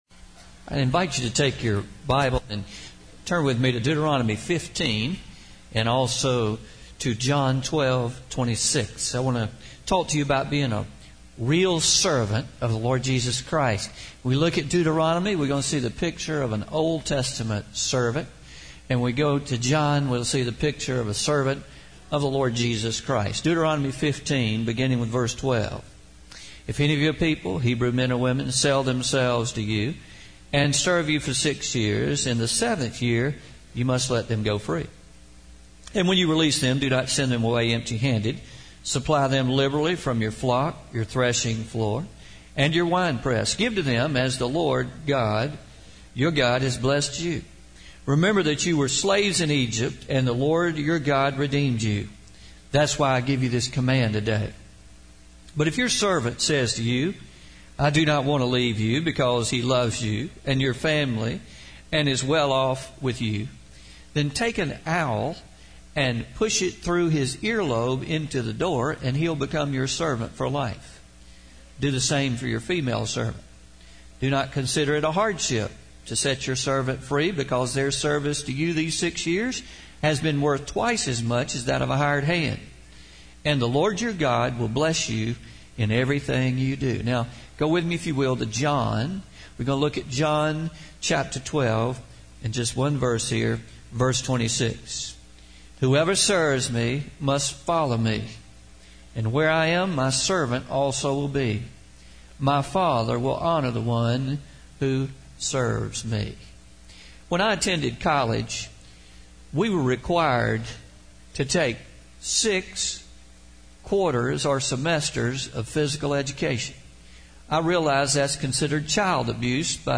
A Real Servant (pre-recorded) – Pleasant Valley South Baptist Church